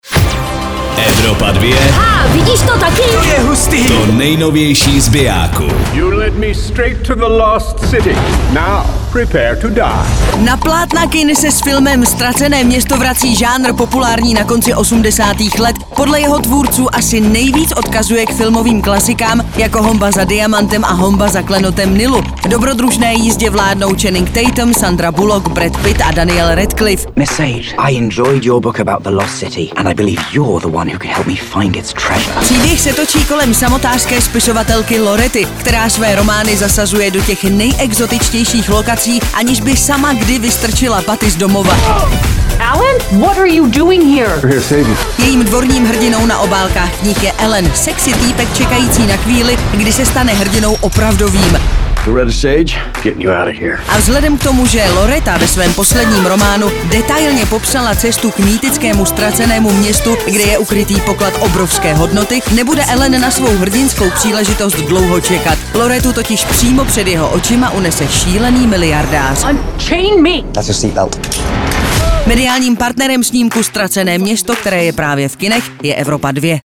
Sandra Bullock and Channing Tatum star in Paramount Pictures‘ „THE LOST CITY.“
filmový trailer